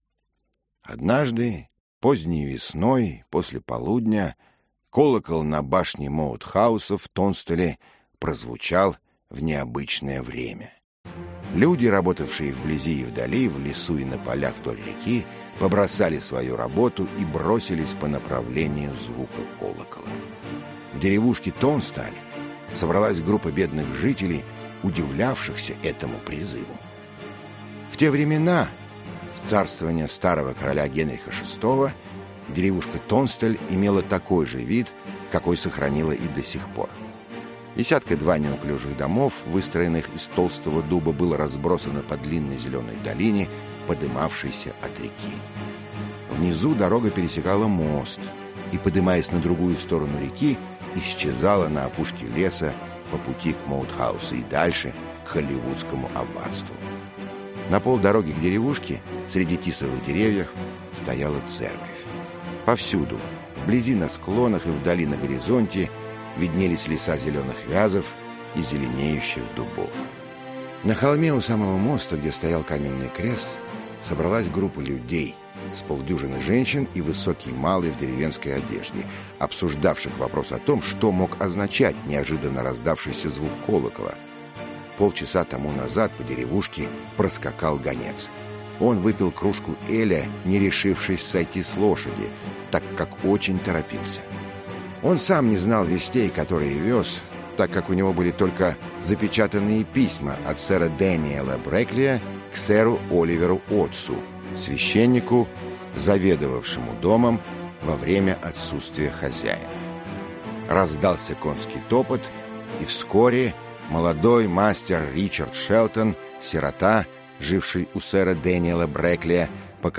Аудиокнига Черная стрела | Библиотека аудиокниг
Aудиокнига Черная стрела Автор Роберт Льюис Стивенсон Читает аудиокнигу Михаил Горевой.